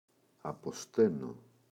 αποσταίνω [apo’steno] – ΔΠΗ